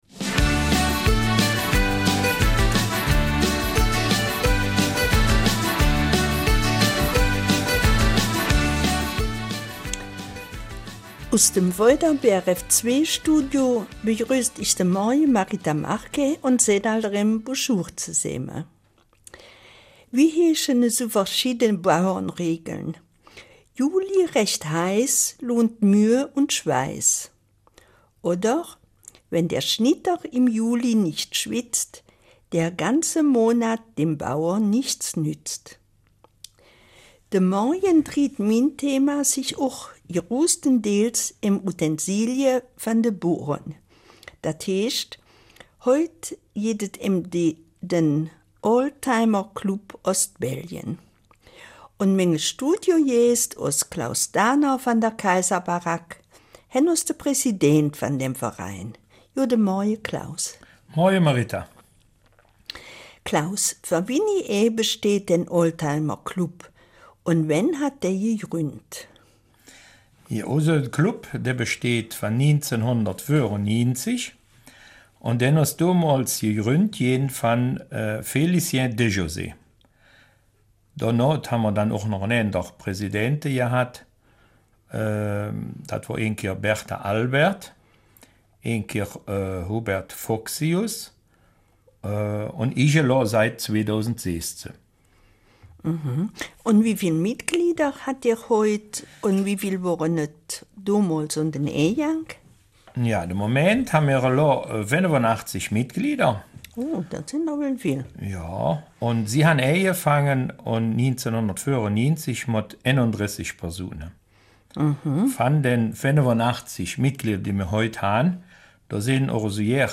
Eifeler Mundart: Oldtimer Club Ostbelgien